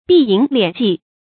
避影斂跡 注音： ㄅㄧˋ ㄧㄥˇ ㄌㄧㄢˇ ㄐㄧˋ 讀音讀法： 意思解釋： 指隱蔽起來，不露形跡。